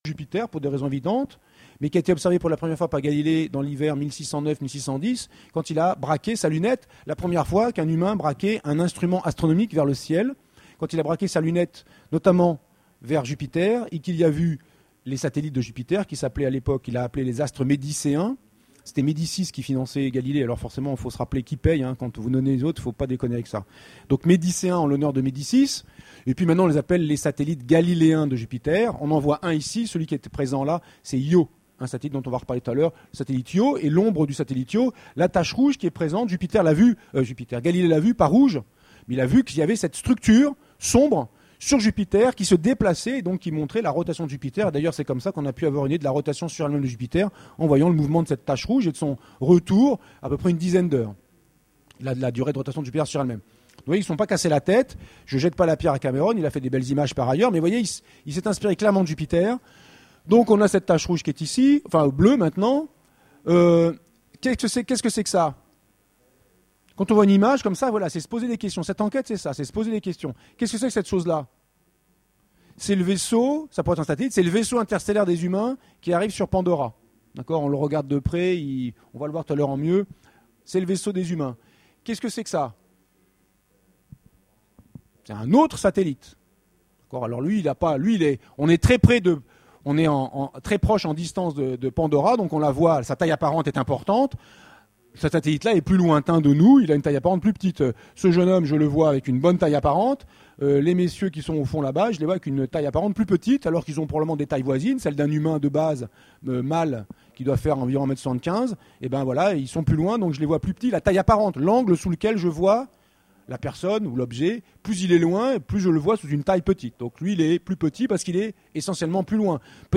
Utopiales 13 : Conférence Le monde d'Avatar est-il réaliste ?